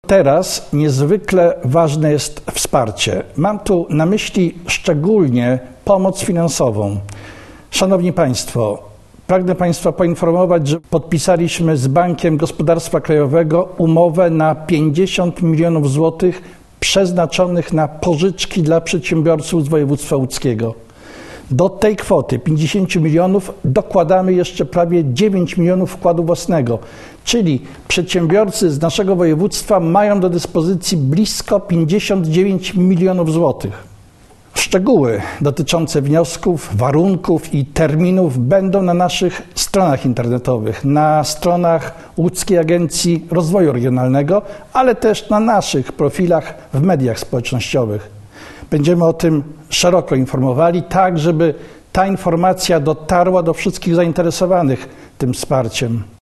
– mówił marszałek województwa łódzkiego, Grzegorz Schreiber.